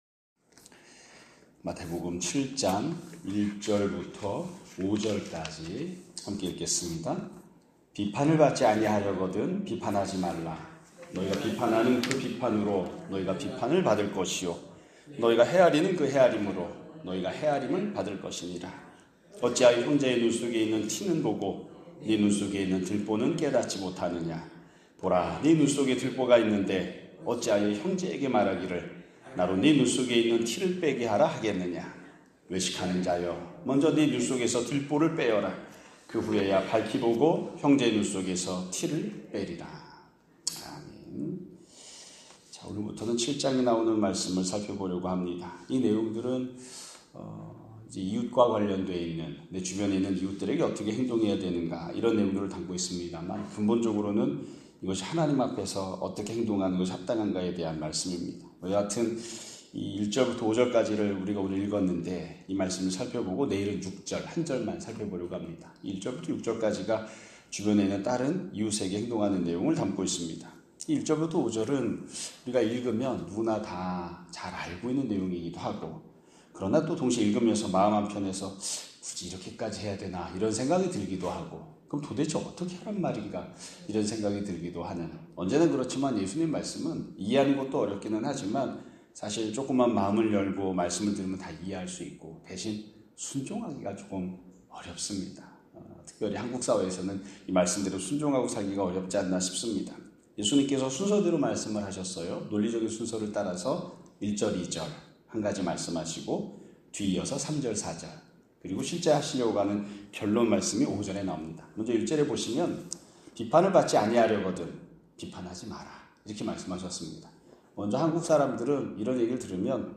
2025년 6월 23일(월요일) <아침예배> 설교입니다.